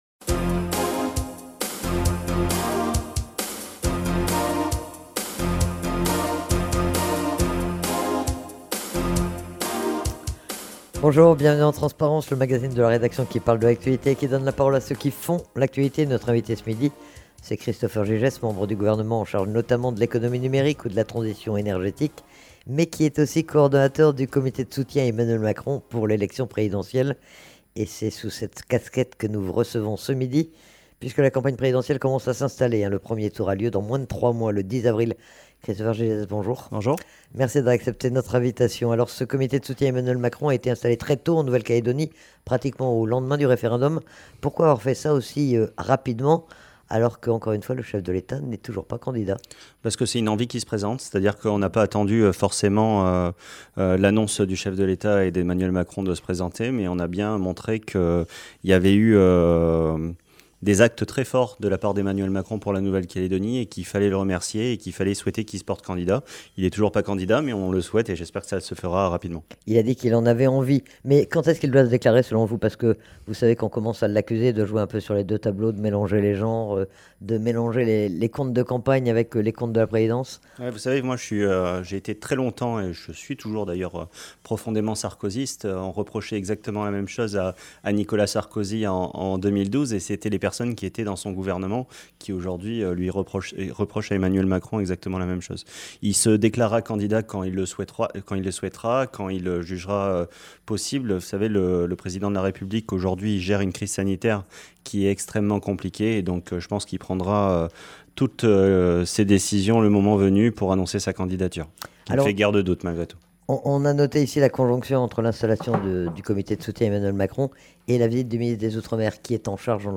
TRANSPARENCE : VENDREDI 14/01/22 13 janvier 2022 à 14:42 Écouter Télécharger Christopher Gygès, membre du gouvernement en charge notamment de l'économie numérique et de la transition énergétique était l'invité du magazine Transparence.